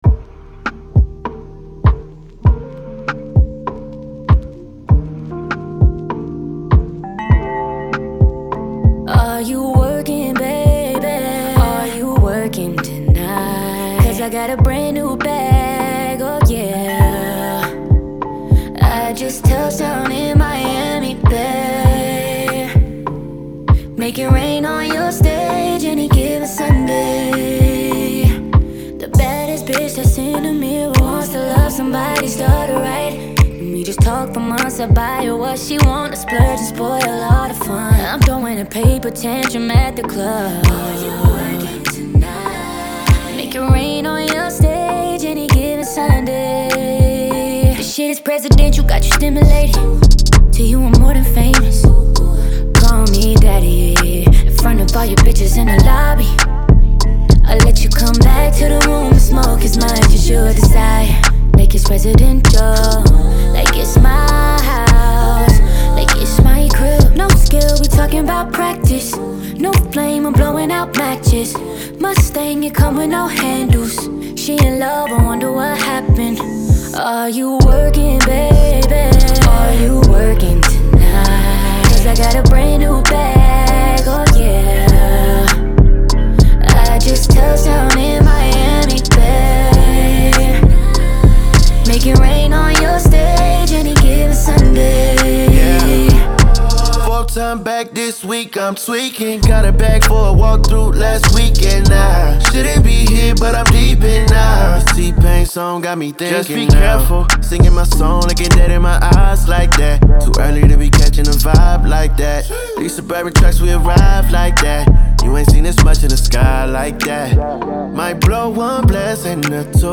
Genre : Funk, R&B, Soul